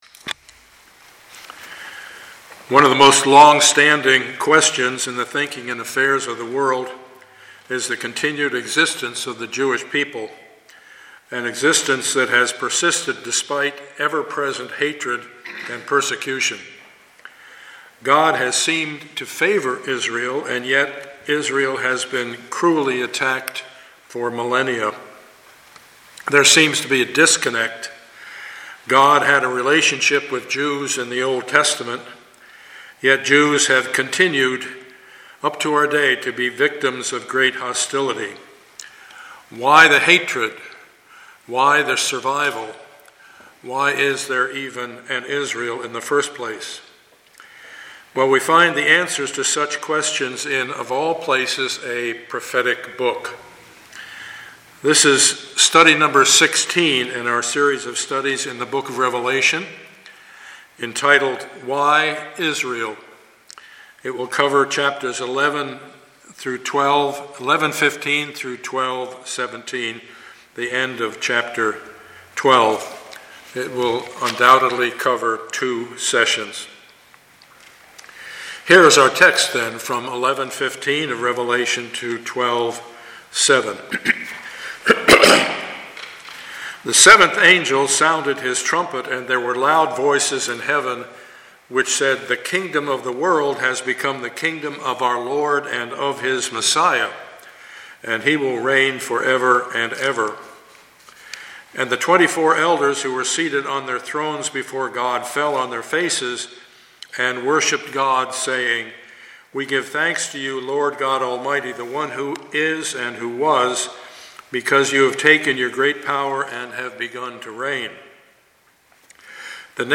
Passage: Revelation 11:15-12:17 Service Type: Sunday morning